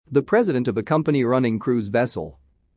以下、設問１）〜４）の不正解答案文を音読したネイティブ音声を出題しました。
不正解答案の読み上げ音声
▶「vessels」の語尾「Z」の発音は脱落しない。